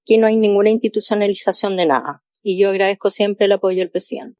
En paralelo, mientras el presidente le entregaba un espaldarazo, Trinidad Steinert se encontraba en Punta Arenas, donde sostuvo una serie de actividades y fue abordada por las críticas de la oposición.
De esta manera, en una escueta declaración, la secretaria de Estado reprochó lo señalado por la oposición y agradeció el respaldo del jefe de Estado.